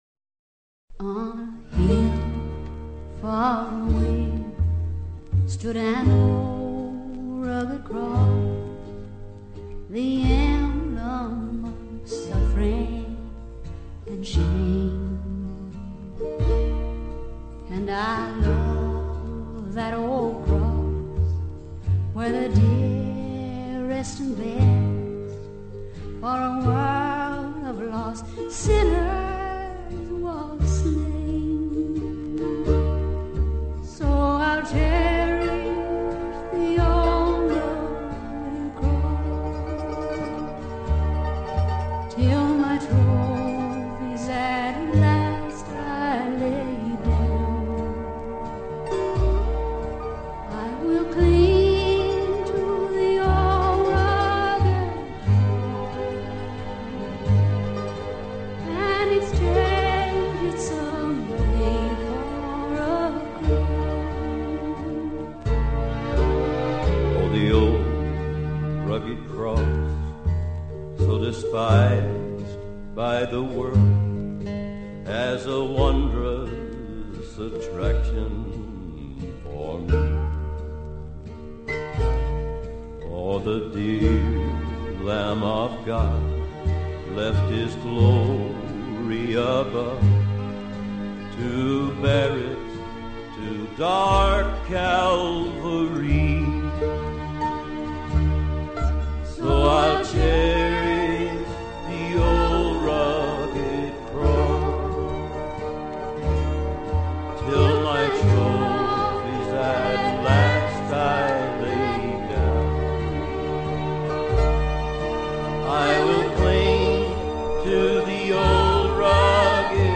Жанр: Folk, World, & Country